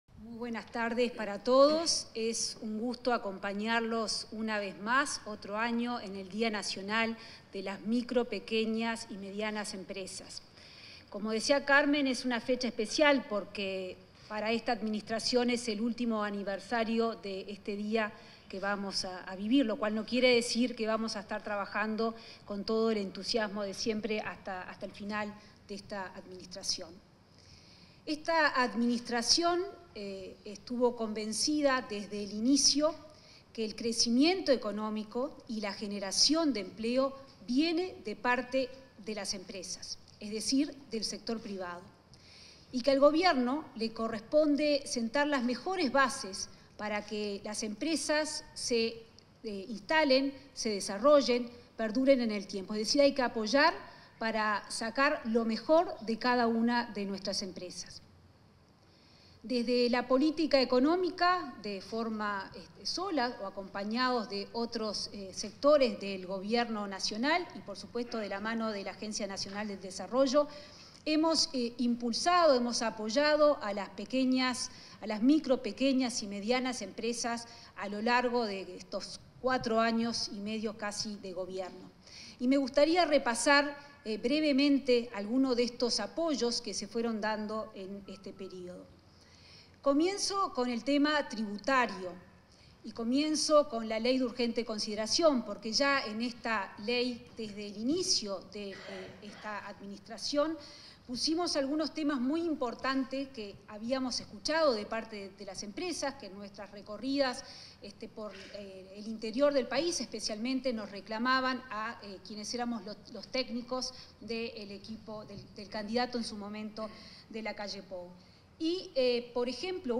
Palabras de la ministra de Economía y Finanzas, Azucena Arbeleche
En el marco de la ceremonia de conmemoración del Día Nacional de las Mipymes, este 13 de agosto, se expresó la ministra de Economía y Finanzas,